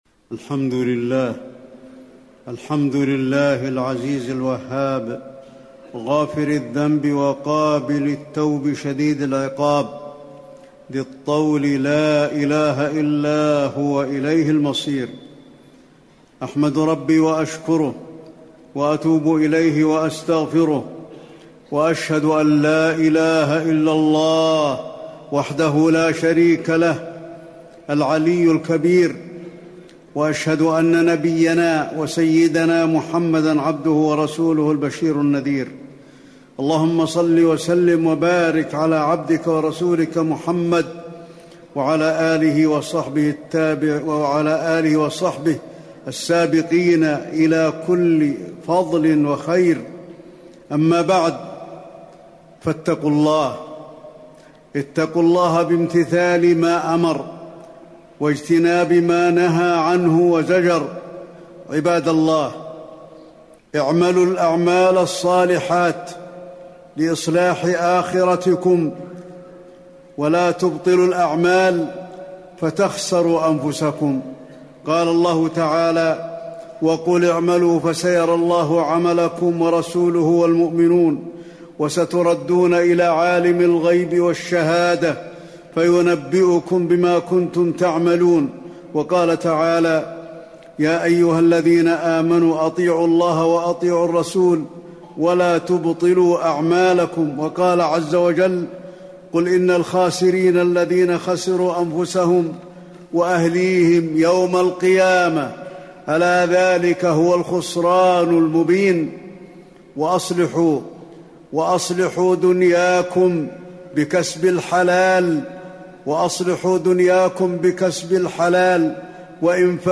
شبكة المعرفة الإسلامية | الدروس | الإيمان بحوض النبي صلى الله عليه وسلم في الآخرة |علي بن عبد الرحمن الحذيفي